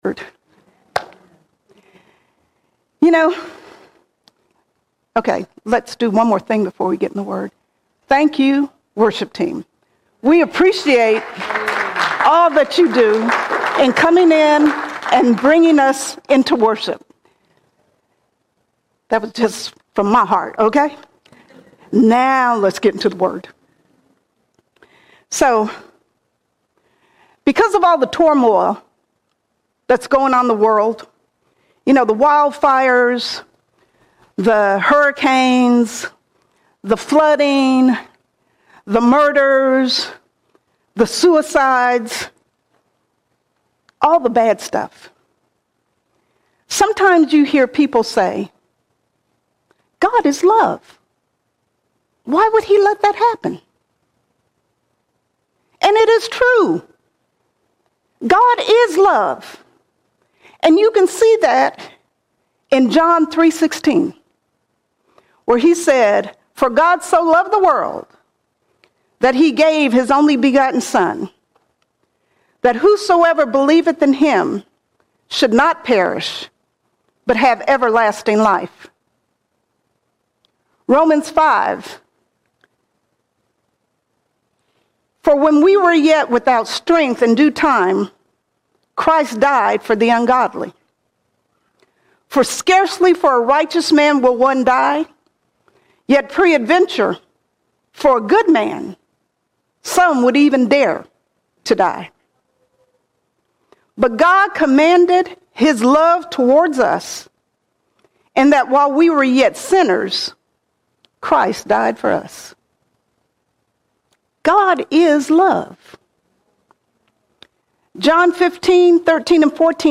20 January 2025 Series: Sunday Sermons All Sermons The Invite The Invite God invites us into a personal, transforming fellowship with His Son, sharing His grace and purpose.